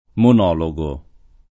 monologo [ mon 0 lo g o ] s. m.; pl. -ghi